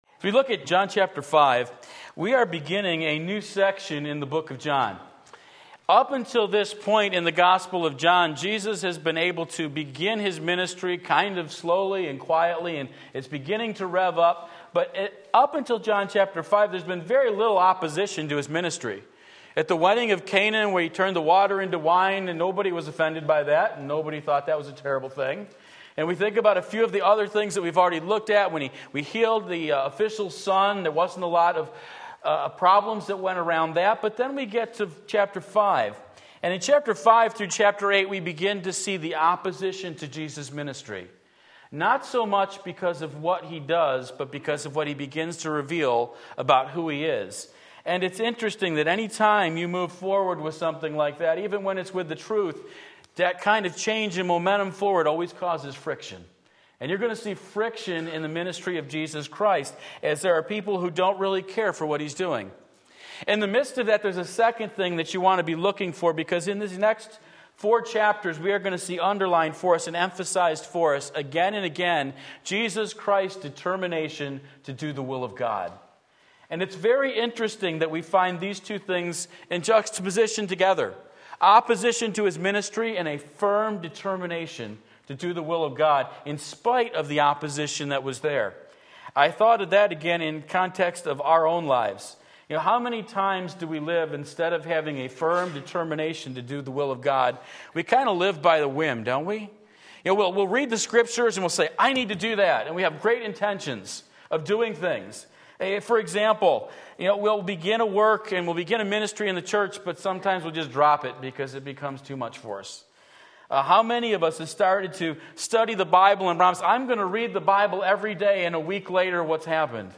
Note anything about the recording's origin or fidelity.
A Question of Authority John 5:1-47 Sunday Morning Service, January 22, 2017 Believe and Live!